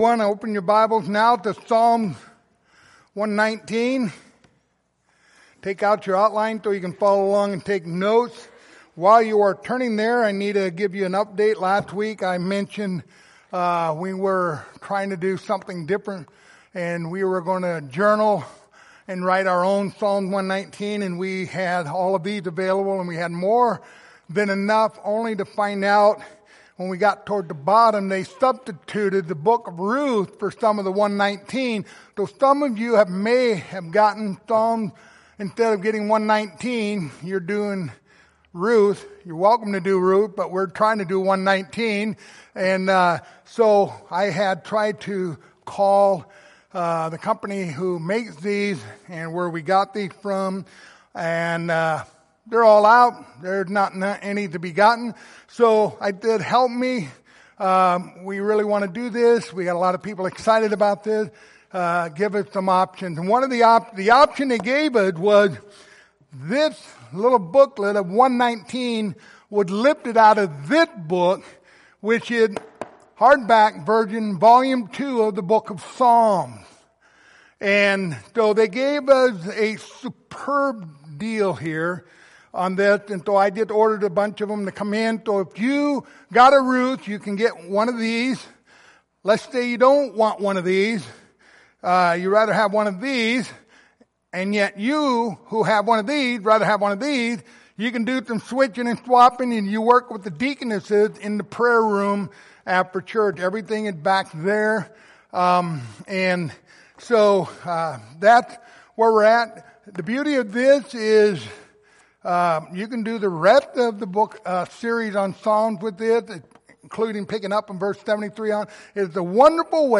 Passage: Psalms 119:1-2 Service Type: Sunday Morning